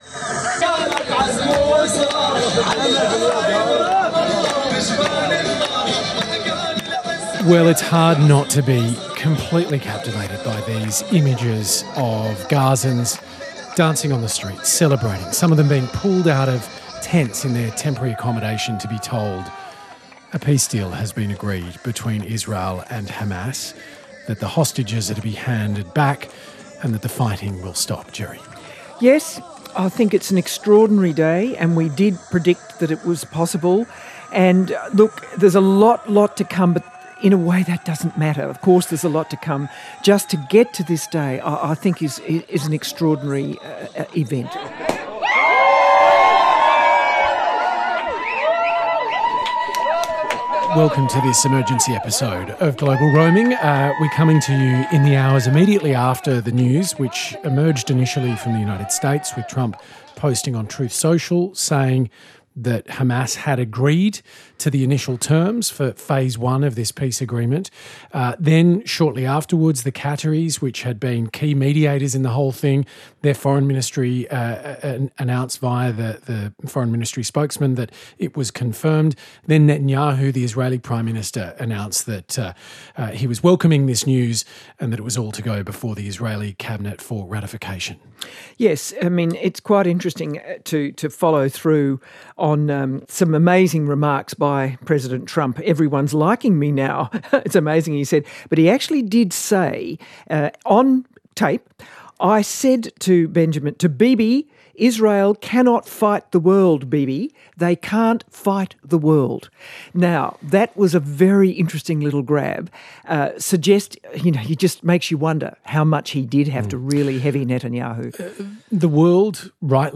They raced into another studio to record a bonus episode of Global Roaming about this enormous development in the Middle East.